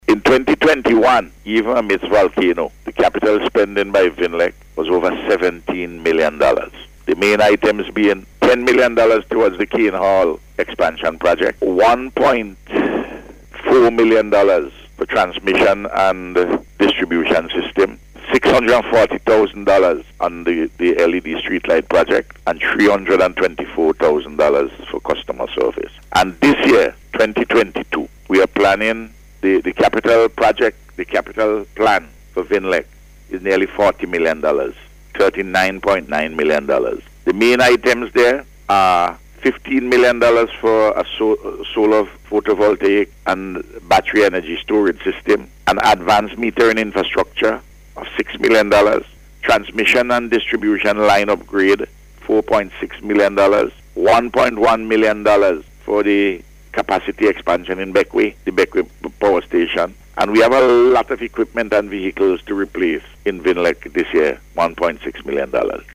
Prime Minister Dr. Ralph Gonsalves discussed upcoming initiatives to take place in this area, during NBC’s Face to Face programme yesterday.